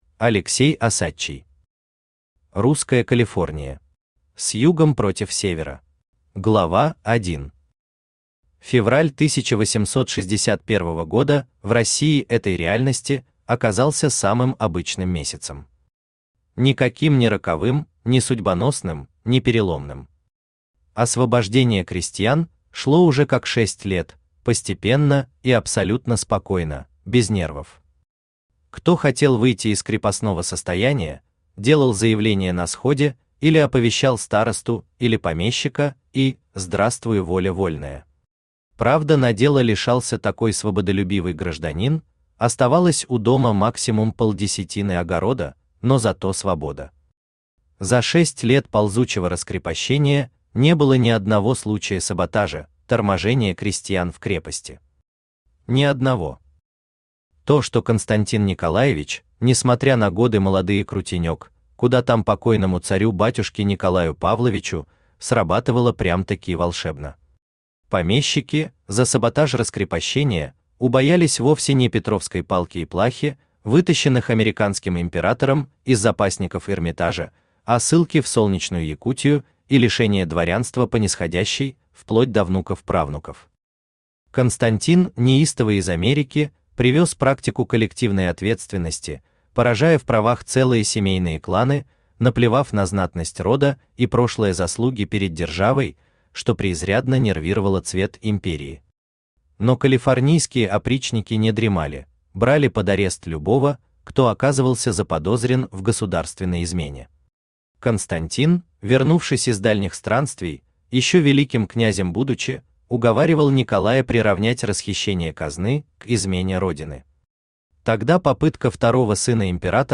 Аудиокнига Русская Калифорния. С Югом против Севера | Библиотека аудиокниг
Aудиокнига Русская Калифорния. С Югом против Севера Автор Алексей Осадчий Читает аудиокнигу Авточтец ЛитРес.